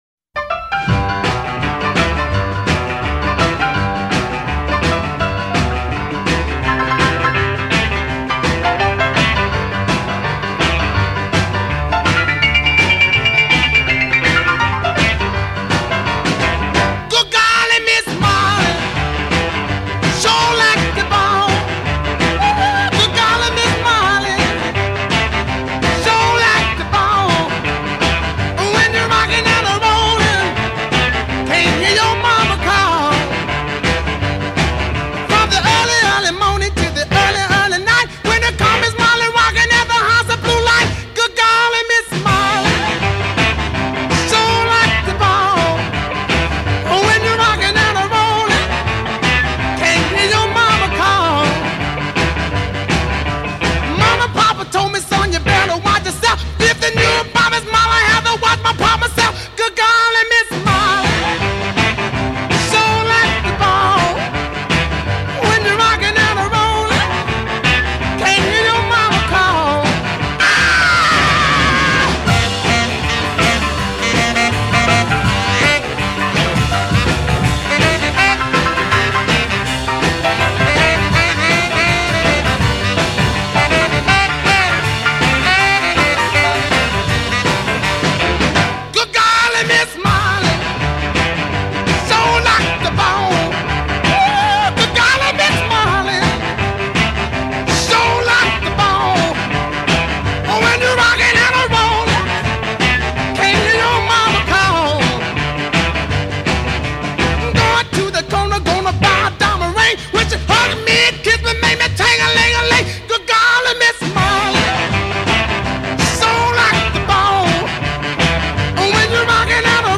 From the Rock 'N Roll icon.